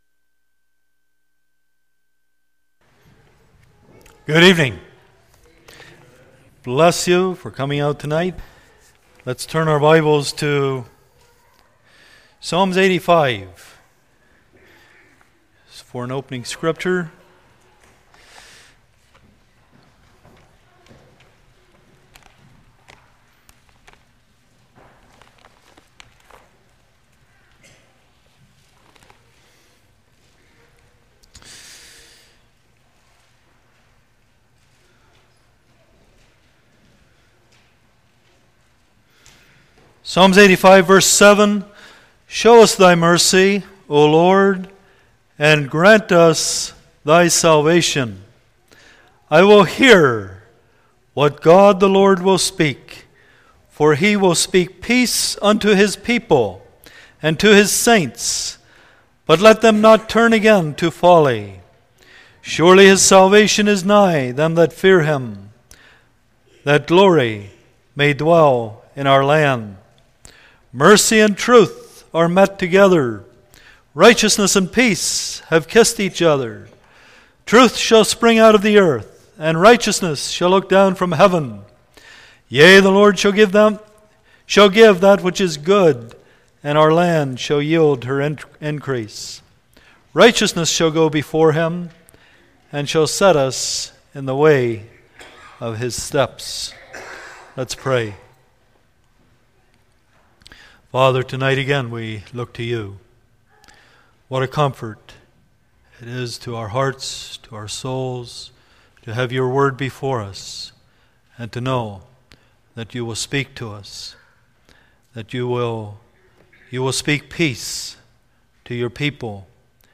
The Church in Crisis Service Type: Saturday Evening Preacher